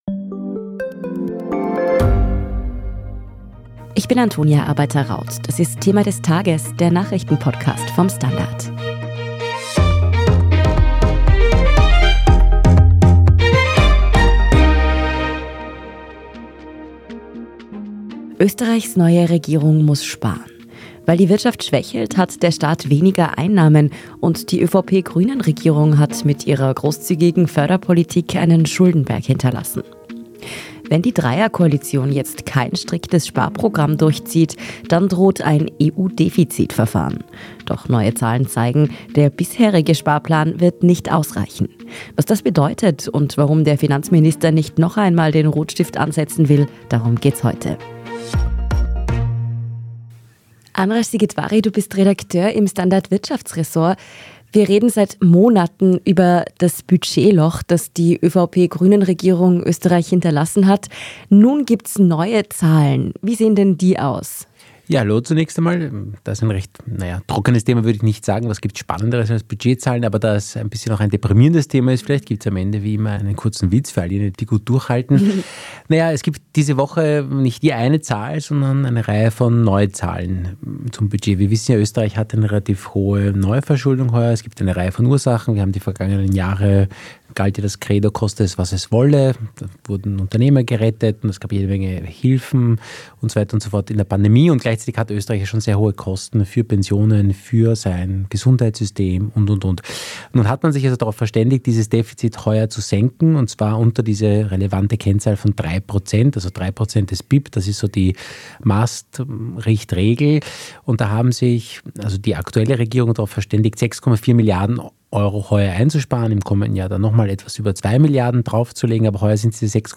"Thema des Tages" ist der Nachrichten-Podcast vom STANDARD.